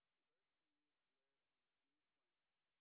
sp01_white_snr0.wav